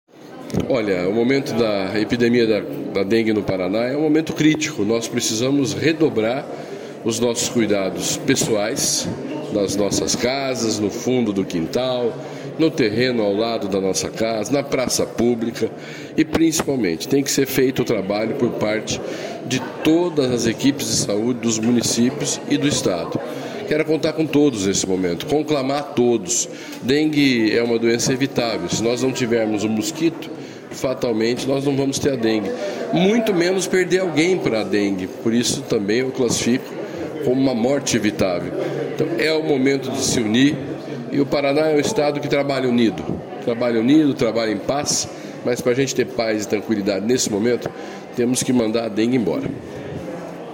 Sonora do secretário da Saúde, Beto Preto, sobre o lançamento da campanha de combate ao mosquito da dengue no Paraná